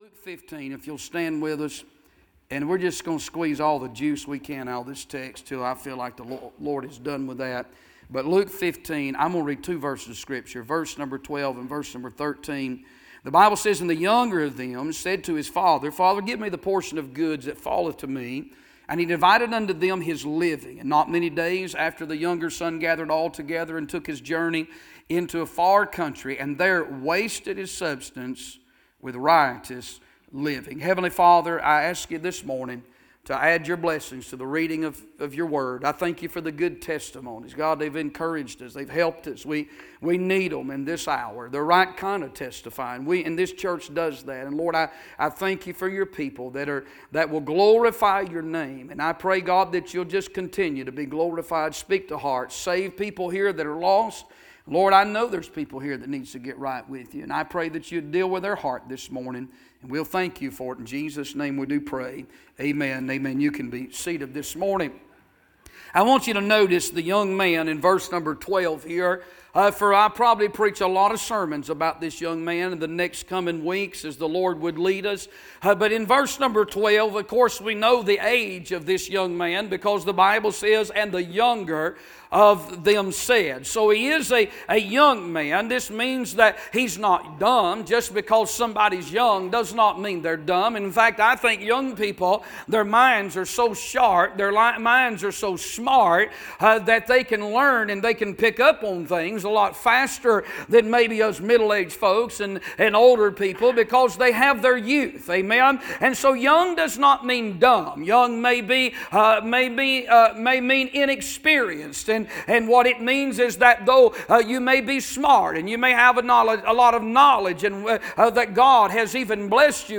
A sermon preached Sunday Morning, on February 22, 2026.